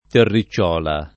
terri©©0la] (oggi lett. terricciuola [